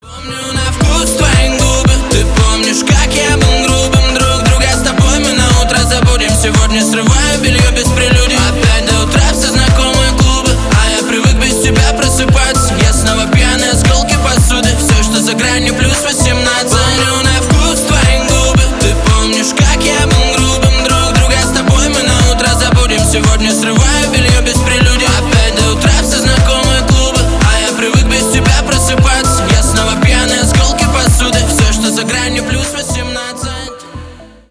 Поп Музыка
тихие
грустные